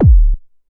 07_Kick_04_SP.wav